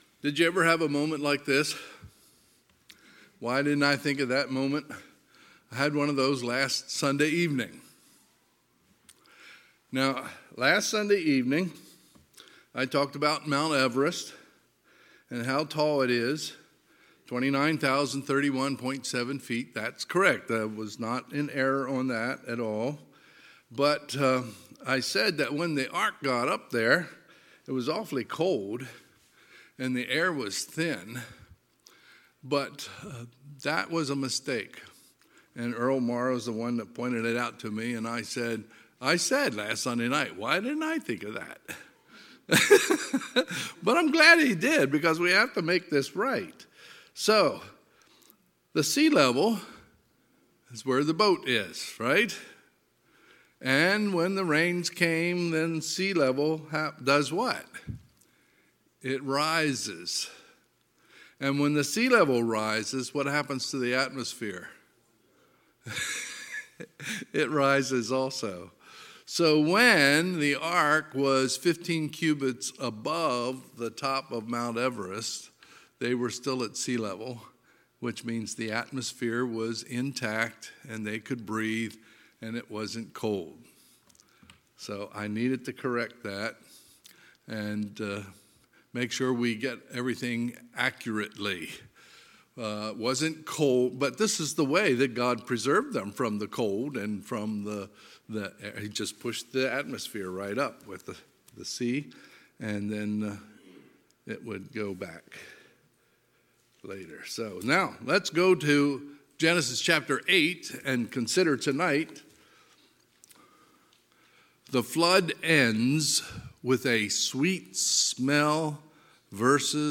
Sunday, March 6, 2022 – Sunday PM
Sermons